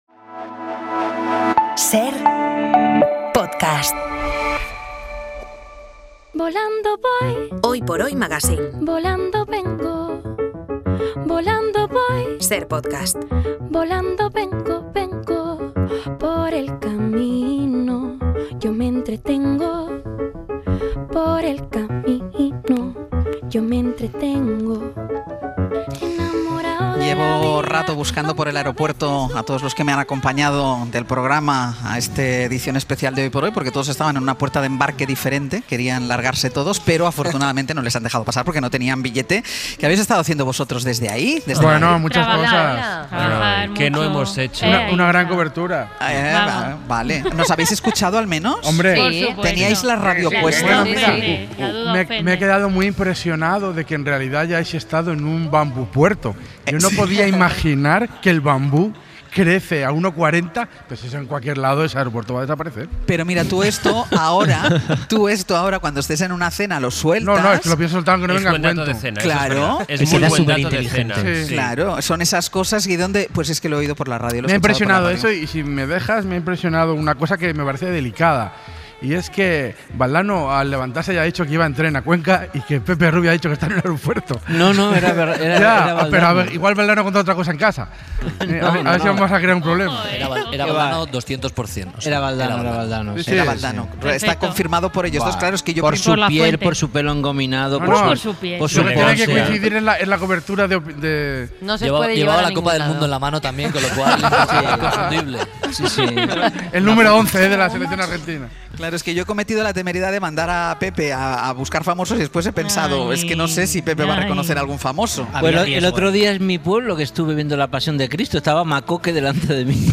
Estando en la Terminal 4 del Aeropuerto Adolfo Suárez Barajas no podíamos hacer otra cosa; contar nuestra anécdotas allí. Empezando por la que ha ocurrido esta misma mañana....